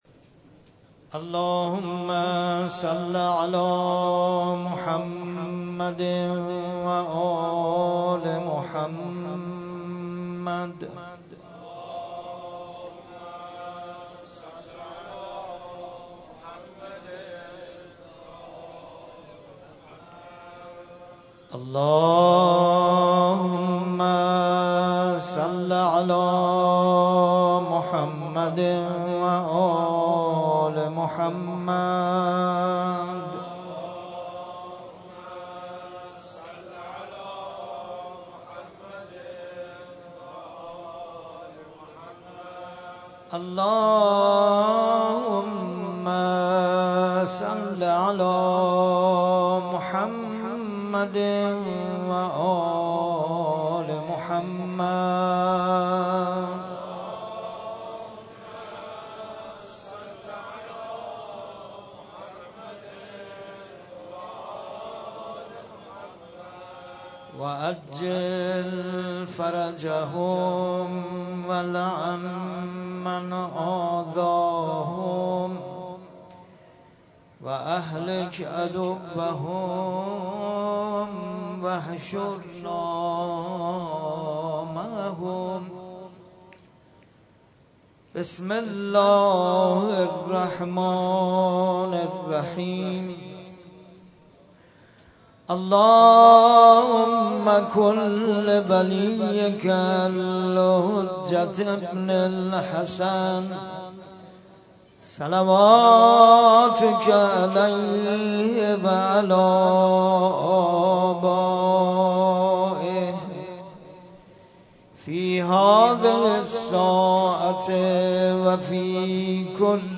در مسجد شهدا برگزار گردید
قرائت دعای ابوحمزه (قسمت اول) ، روضه حضرت امام رضا (علیه السلام)